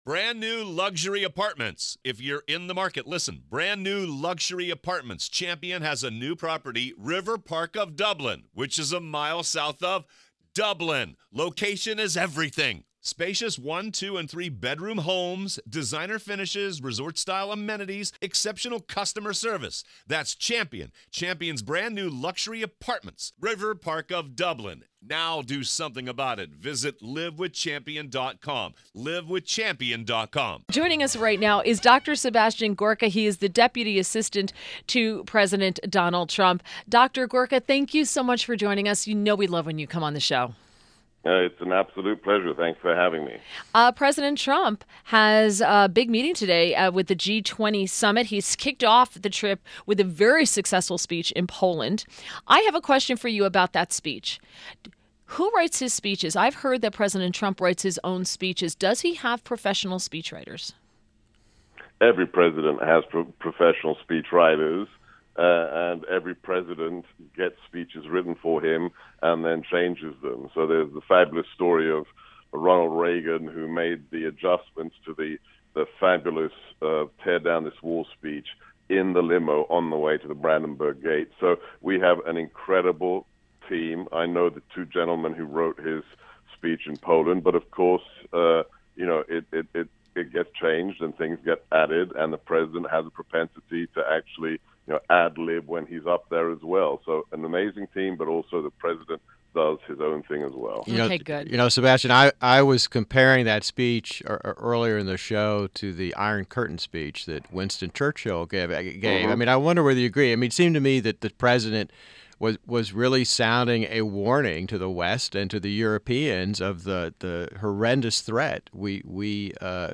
WMAL Interview - DR. SEBASTIAN GORKA 07.07.17